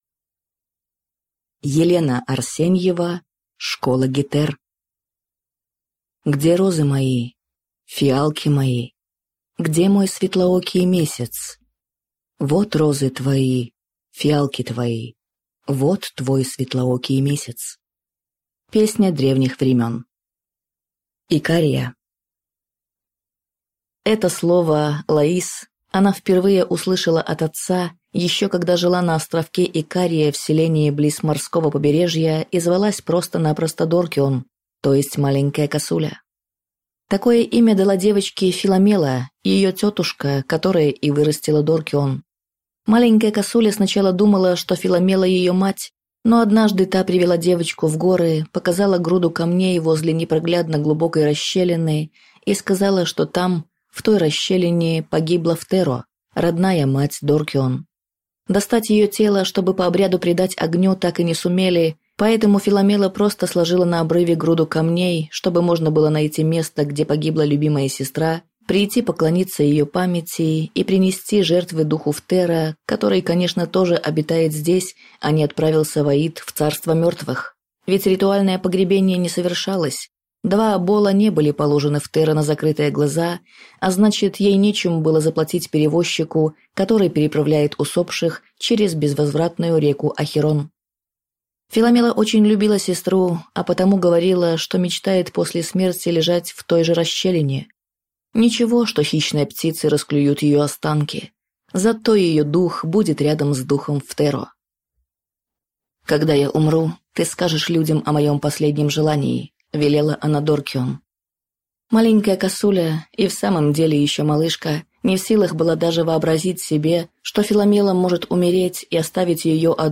Аудиокнига Школа гетер | Библиотека аудиокниг